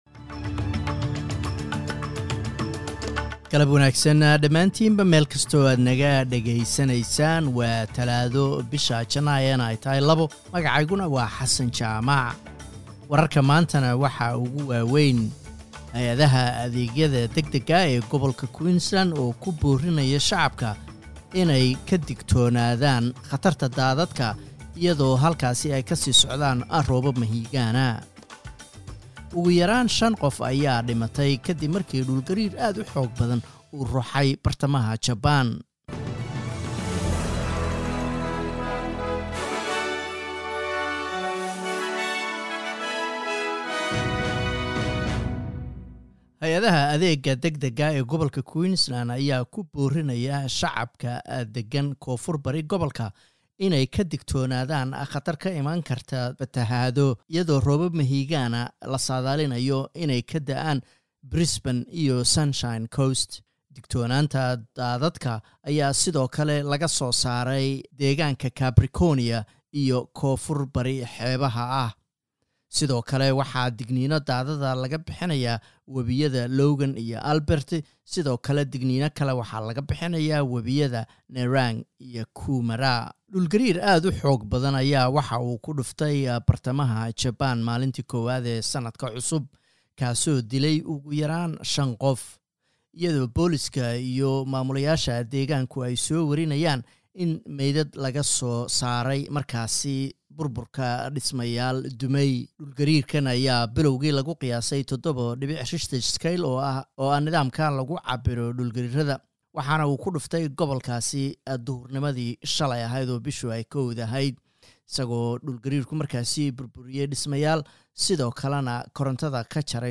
Wararka SBS Somali